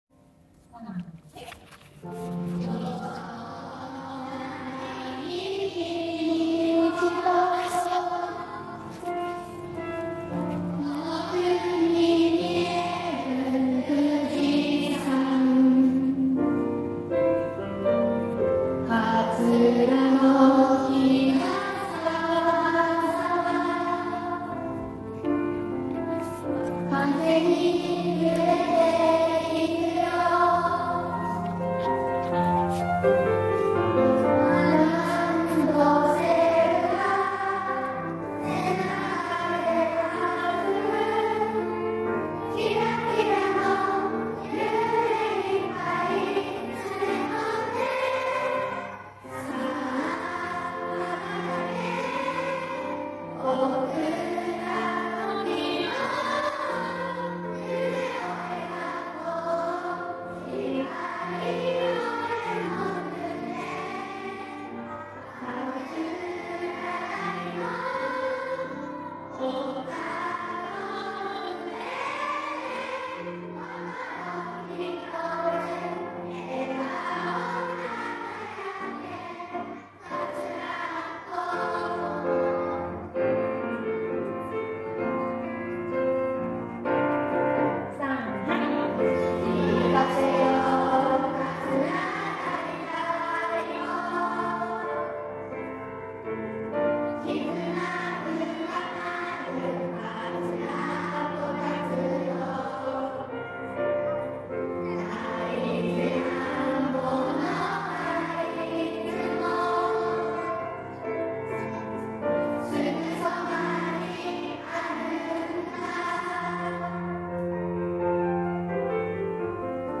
５５周年記念ウインターコンサートでの合唱